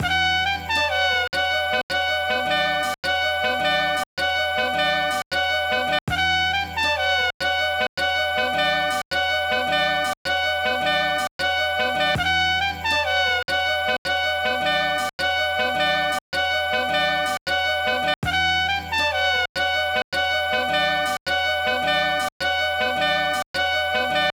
Bridge Jazz Sample.wav